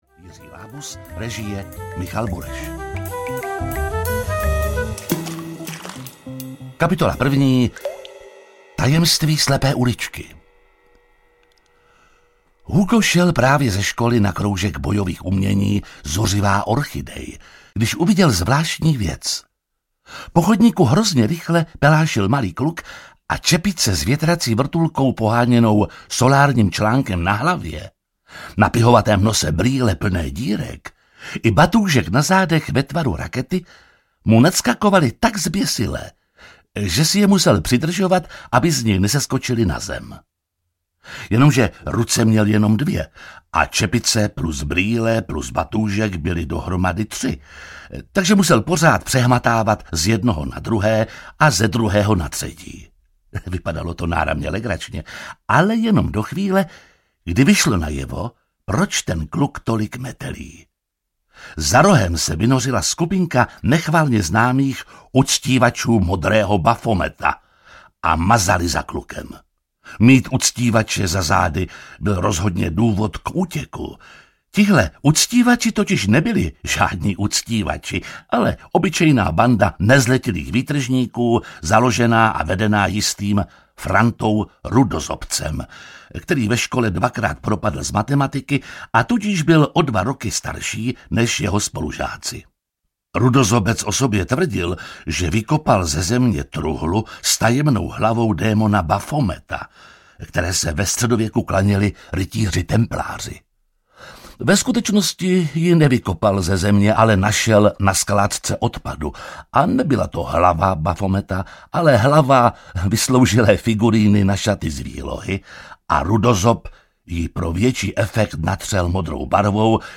H2O a tajná vodní mise audiokniha
Ukázka z knihy
• InterpretJiří Lábus